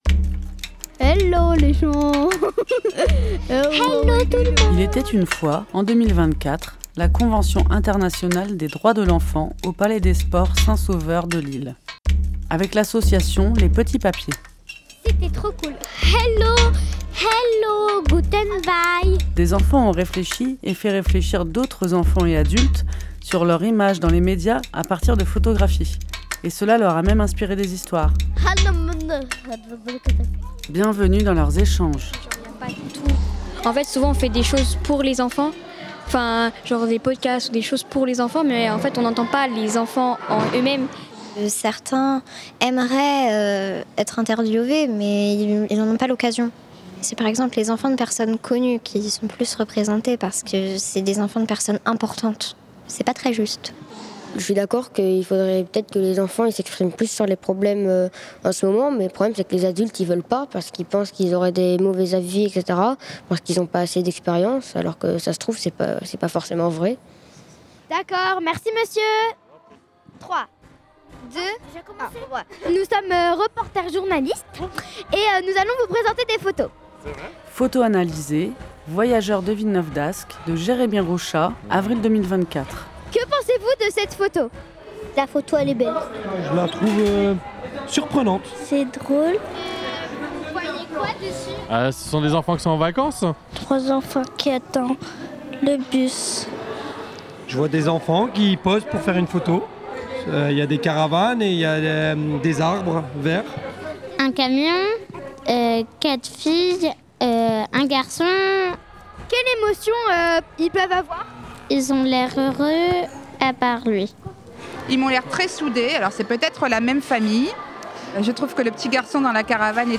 Lancée depuis le confinement, la ligne ouverte est le rdv du quartier Moulins : habitant.e.s, asso et invité.e.s en tous genres échangent sur les actualités du quartier, ses ressources, ses sujets importants et toutes les histoires qui font la vie du quartier.